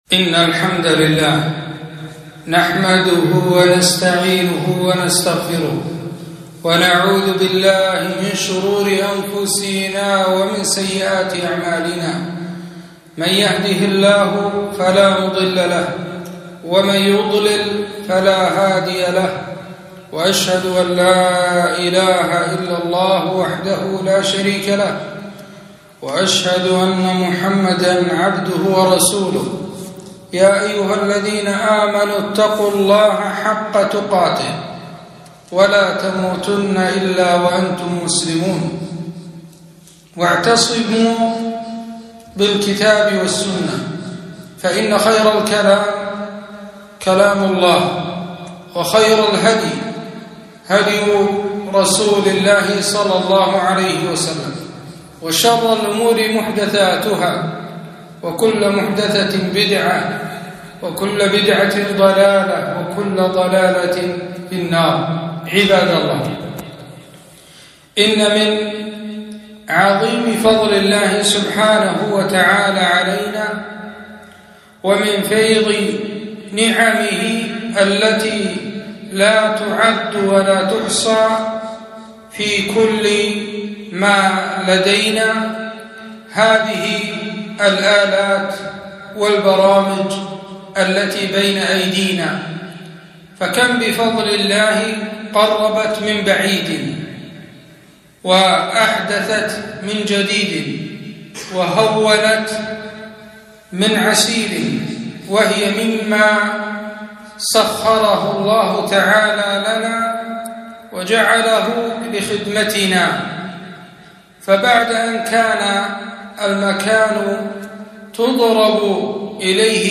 خطبة - وسائل التواصل الاجتماعي وأثرها على الفرد والمجتمع - دروس الكويت